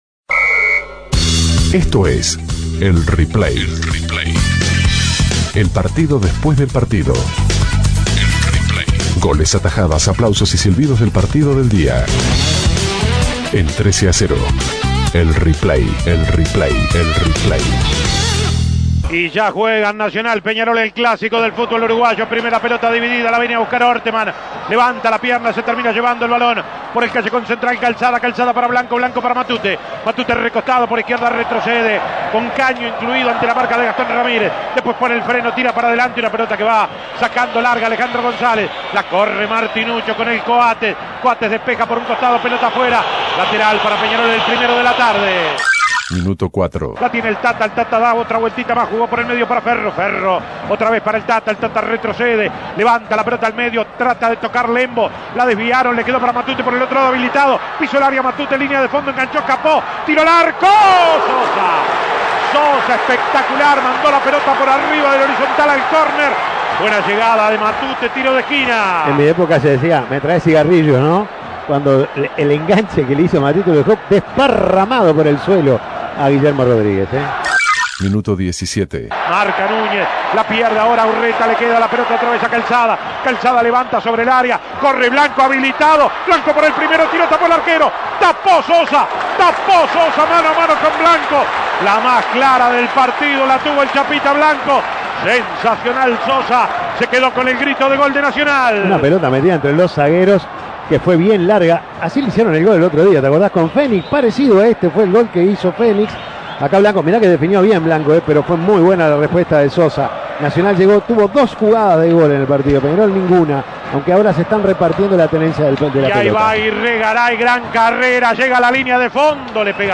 Escuche las mejores jugadas del empate sin goles entre Peñarol y Nacional. A pesar de un gran primer tiempo de ambos equipos, en el complemento no se sacaron ventaja y terminaron 0-0 .